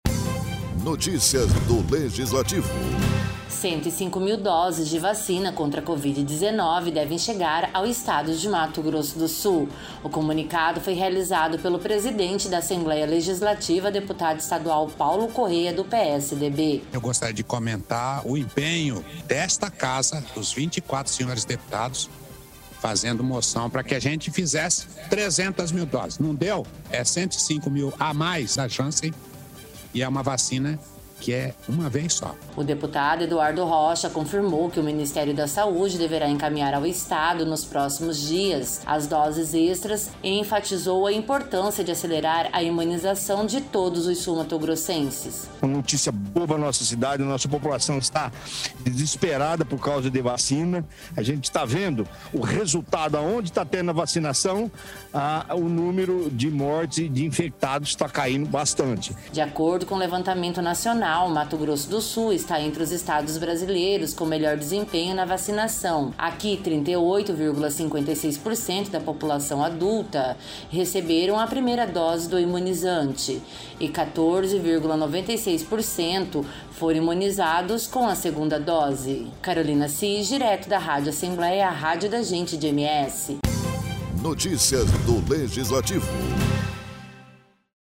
O presidente da Assembleia Legislativa de Mato Grosso do Sul, deputado Paulo Corrêa, do PSDB, fez um comunicado no ínicio da sessão plenária, que o Estado vai receber 105 mil doses extra de vacina Janssen.